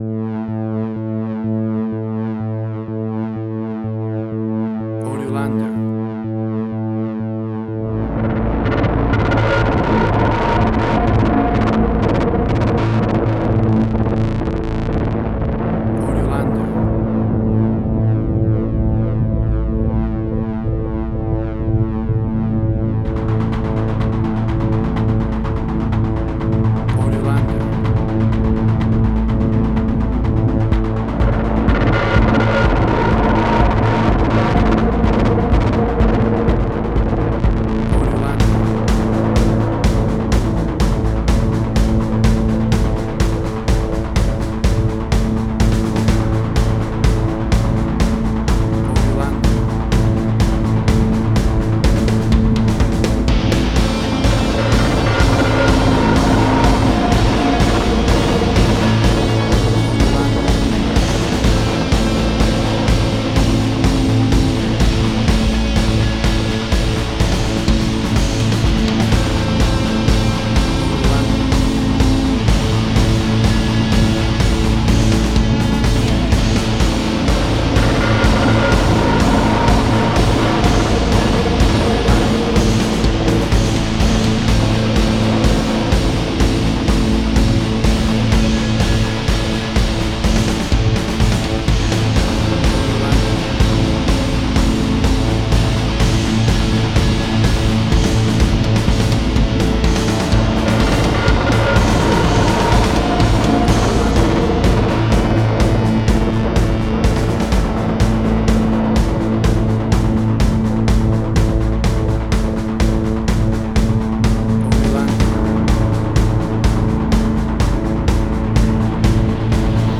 Modern Science Fiction Film, Similar Tron, Legacy Oblivion.
Tempo (BPM): 125